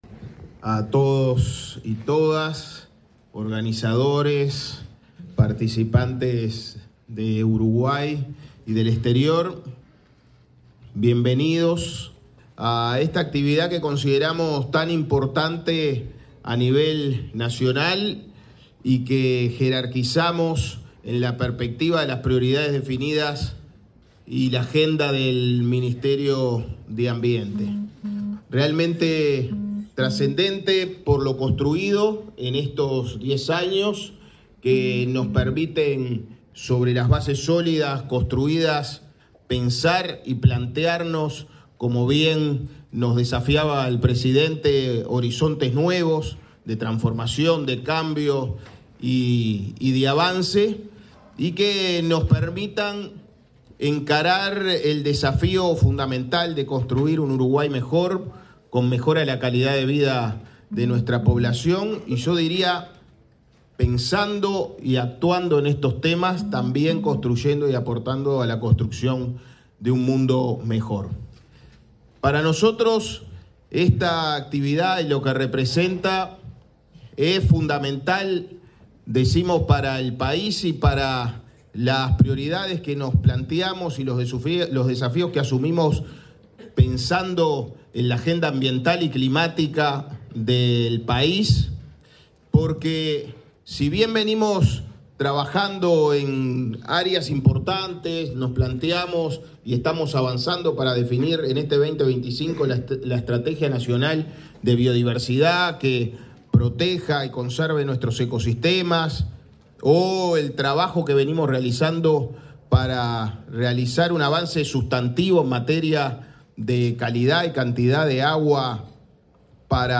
Palabras del ministro de Ambiente, Edgardo Ortuño
El titular del Ministerio de Ambiente, Edgardo Ortuño, expuso en la apertura del XI Congreso Latam Renovables: Energía Inteligente, que se realiza en